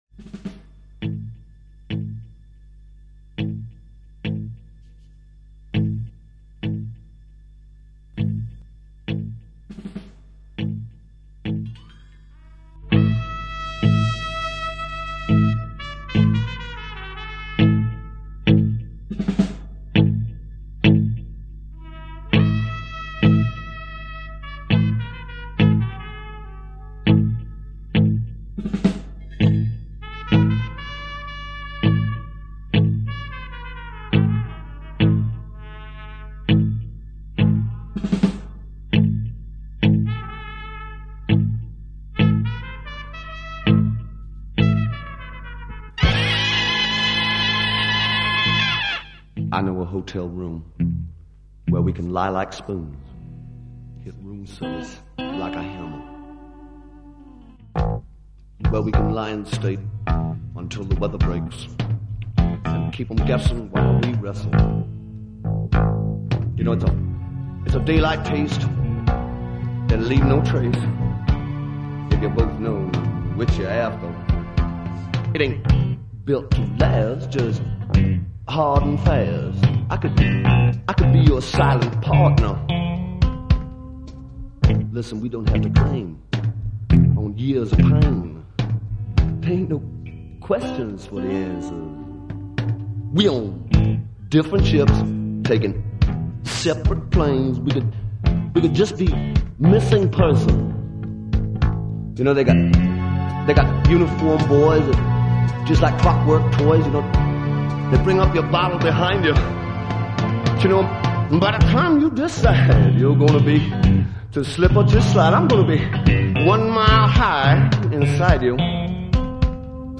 drums
guitar